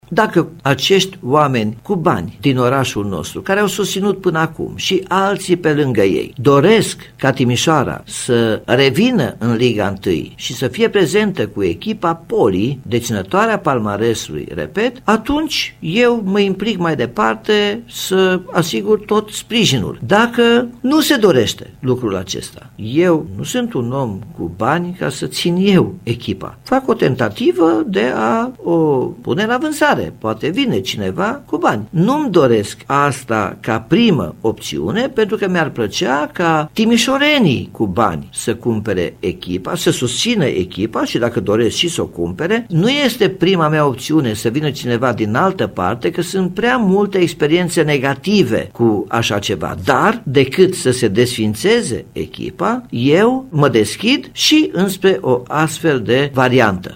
Primarul municipiului de pe Bega, Nicolae Robu, a dat de înțeles că e dispus într-o ultimă variantă să accepte vânzarea actualului club, chiar dacă Timișoara fotbalistică a trăit experiențe nefericite cu eventuali investitori privați: